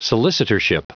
Prononciation du mot solicitorship en anglais (fichier audio)
Prononciation du mot : solicitorship